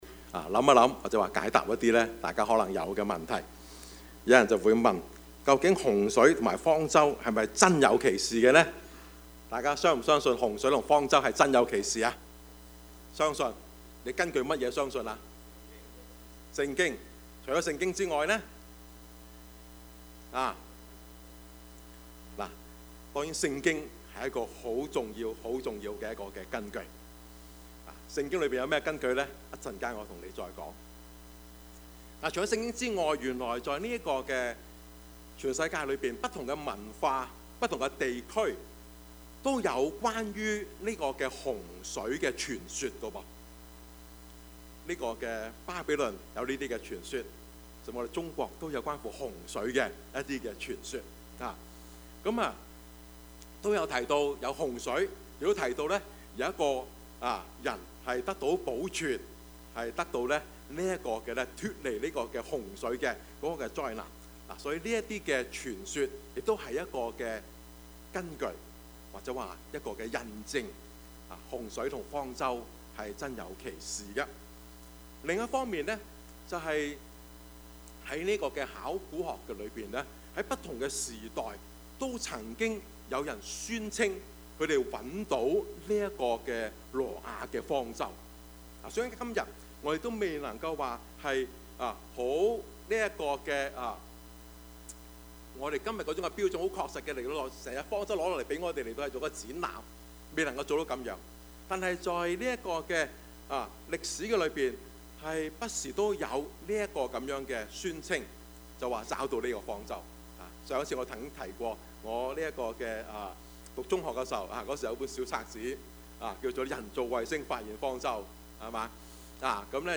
Service Type: 主日崇拜
飾 Topics: 主日證道 « 聽得入耳 積極人生 »